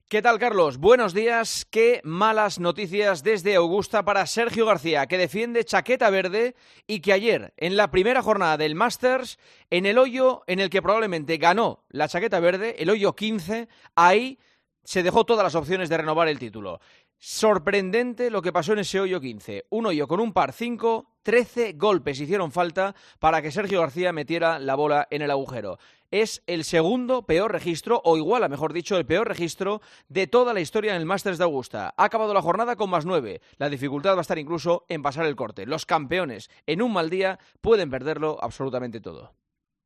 Escucha el análisis de la actualidad deportiva del director de 'El Partidazo' de COPE